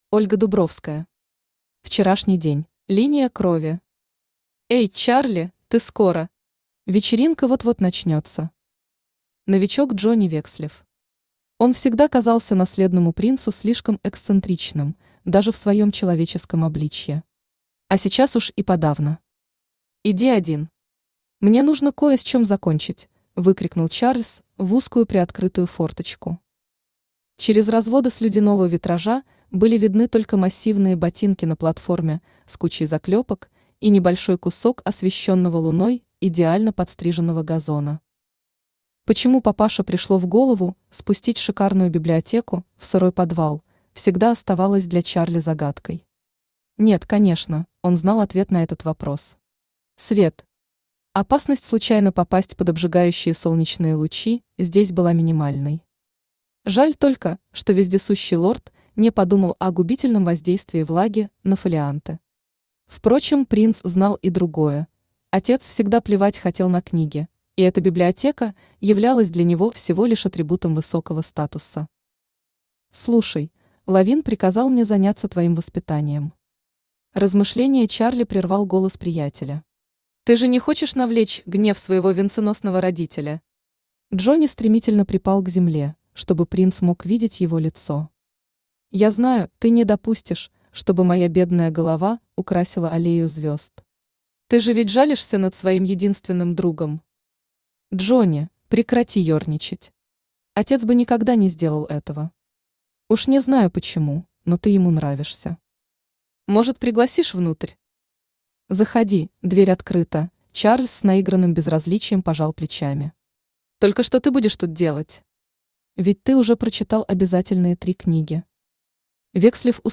Аудиокнига Вчерашний день | Библиотека аудиокниг
Aудиокнига Вчерашний день Автор Ольга Дубровская Читает аудиокнигу Авточтец ЛитРес.